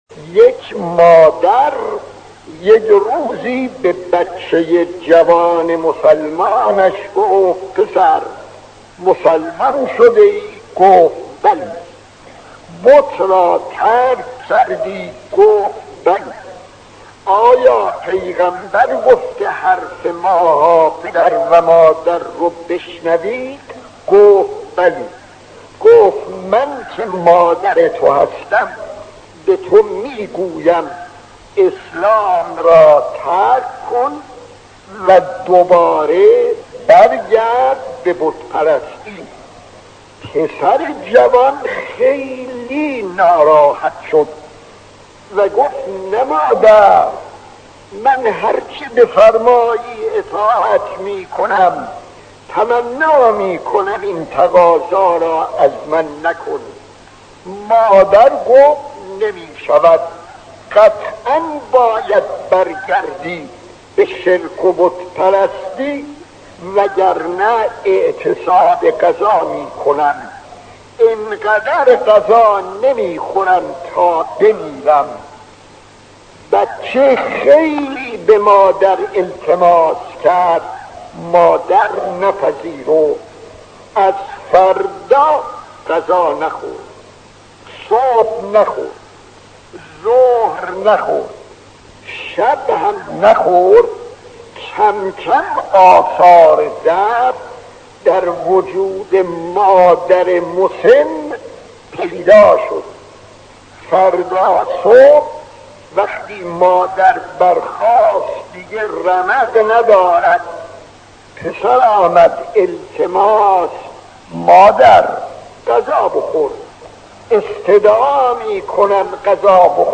داستان 50 : اطاعت از والدین جز در دعوت به شرک خطیب: استاد فلسفی مدت زمان: 00:06:53